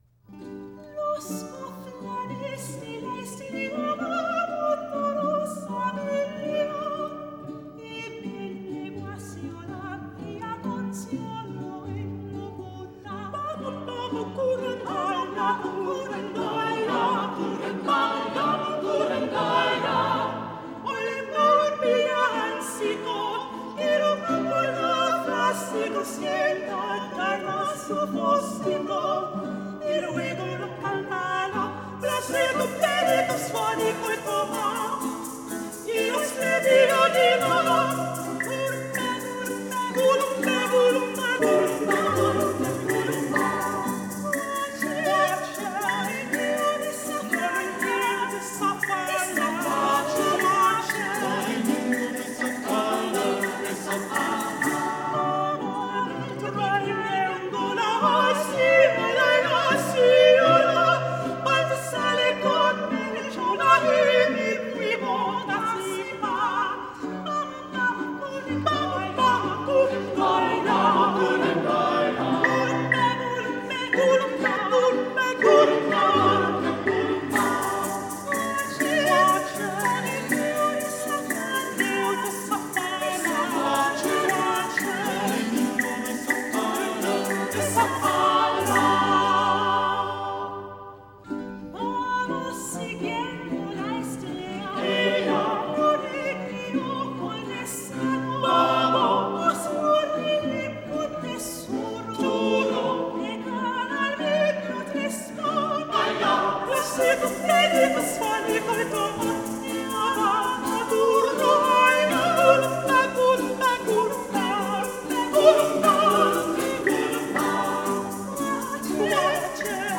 Música vocal
Música religiosa
Música tradicional
Coral